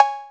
808-Cowbell5.wav